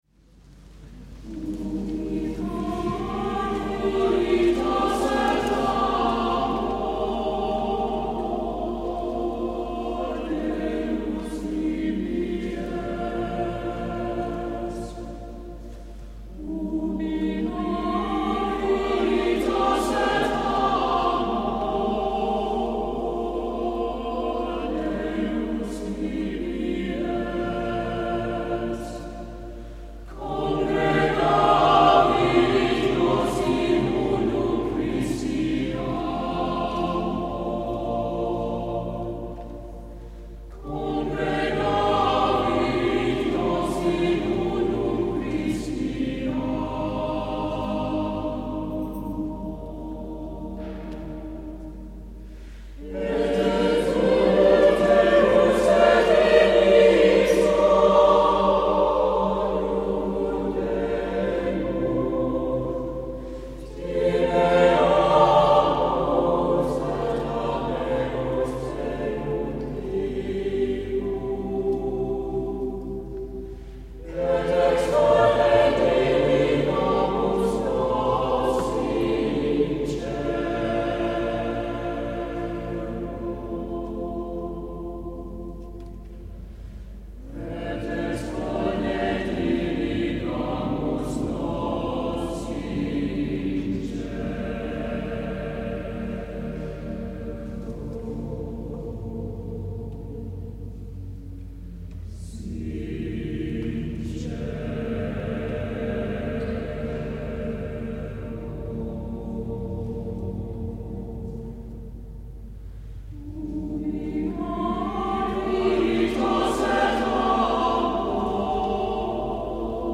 volver al menu principal "Castillo Interior" Concierto de Coro en la Catedral de �vila en 1995 Escuchar el UBIS CARITAS de Maurice Durufl�, pulsando el logo verde y volver a la ventana de las im�genes: